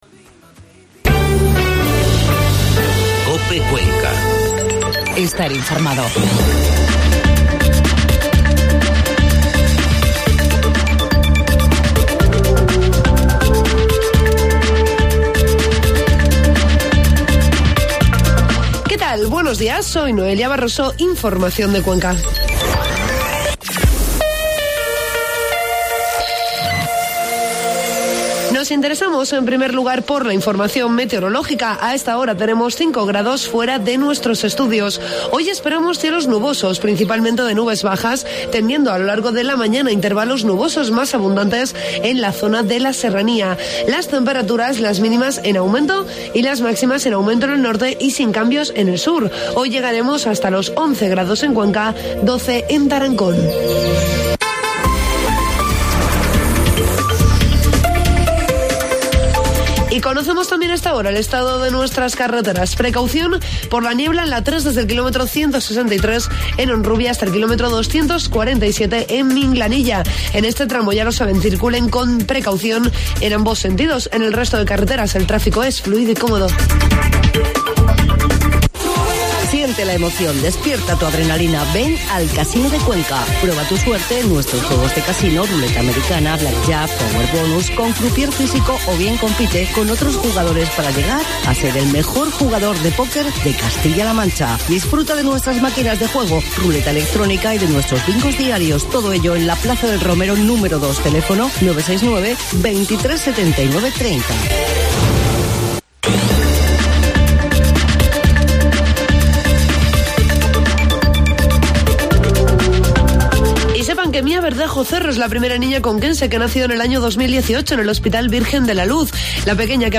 Informativo matinal 2 de enero
AUDIO: Informativo matinal